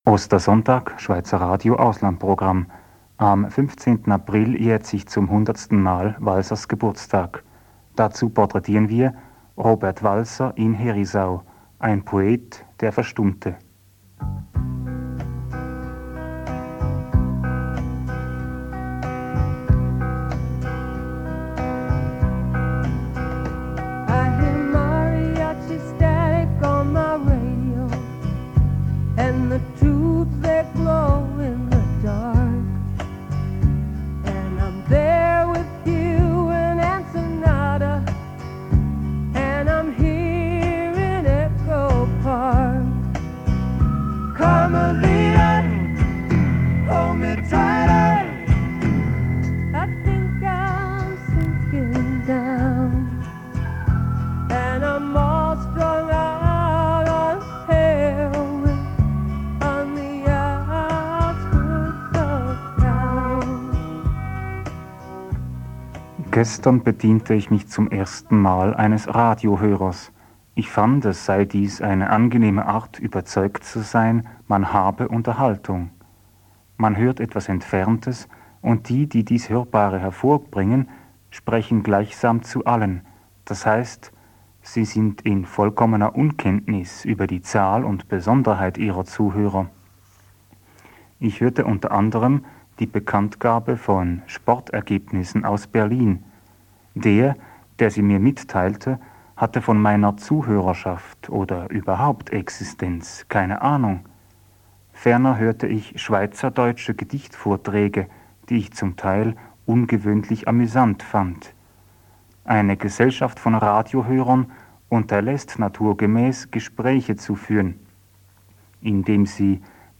Schweizer Radio International (Schweizerischer Kurzwellendienst), Bern, 26. März 1978.